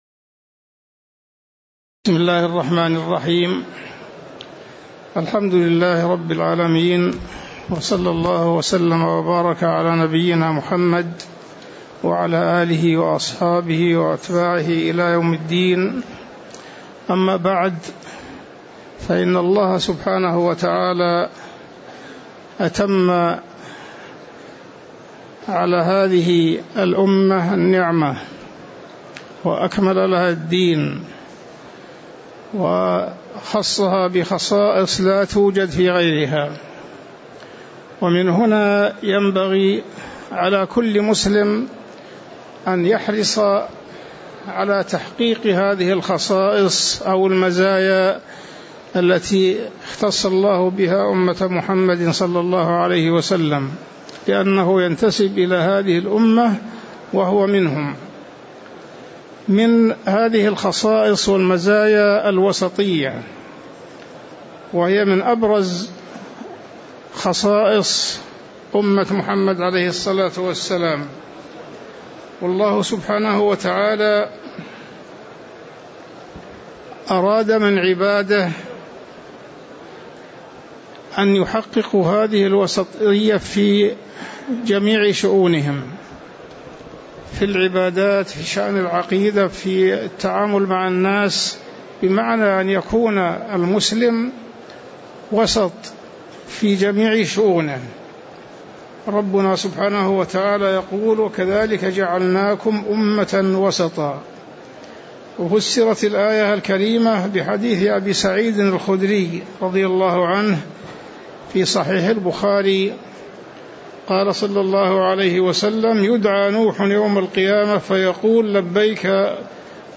تاريخ النشر ١٤ ذو القعدة ١٤٤٥ هـ المكان: المسجد النبوي الشيخ: عبدالله التركي عبدالله التركي المسلمون أمة وسط